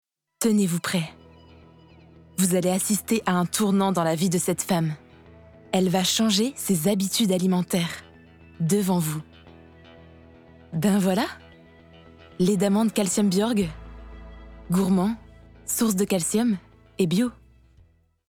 Voix OFF - Pub Spartoo (Espagnol)
- Mezzo-soprano